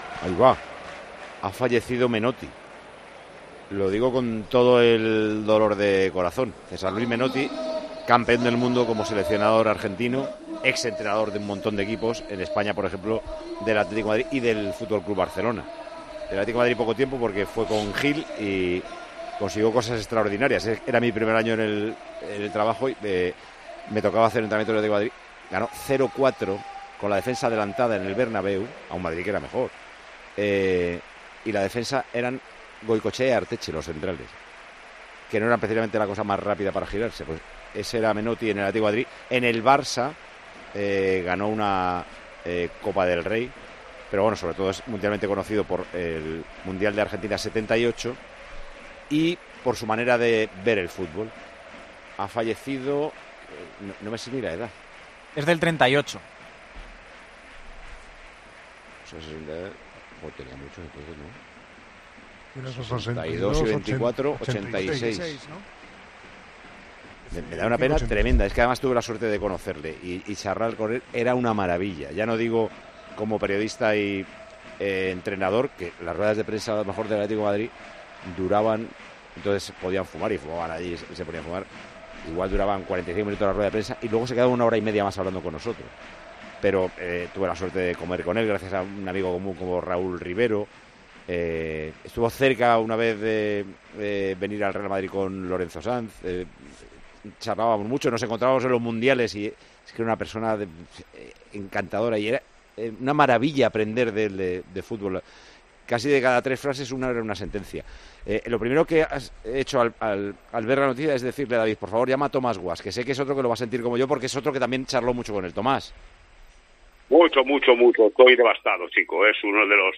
Paco González y Tomás Guasch se emocionan al recordar la figura de César Luis Menotti: "Irrepetible"